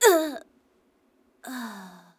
人声采集素材
• 声道 單聲道 (1ch)